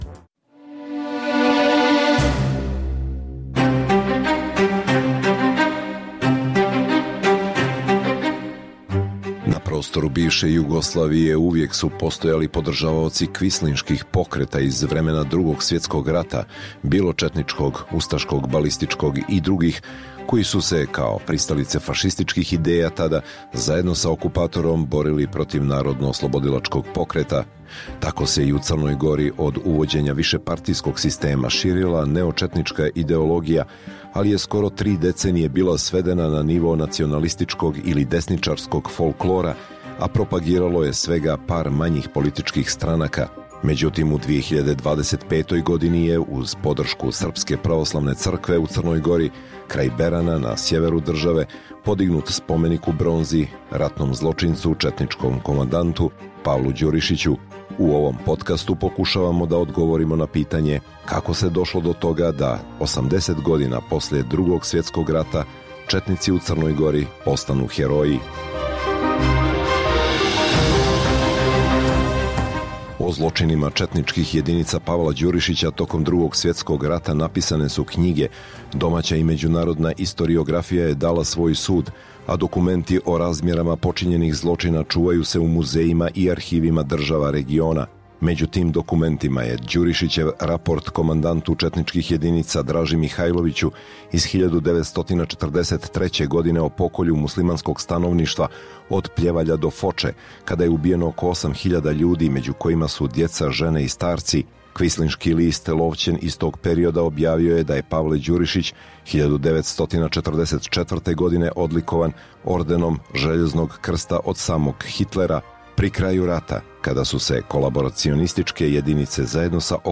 Emisija u kojoj dublje istražujemo aktuelne događaje koji nisu u prvom planu kroz intervjue, analize, komentare i reportaže.